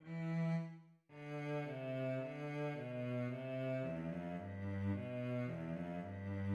Second movement: Allegretto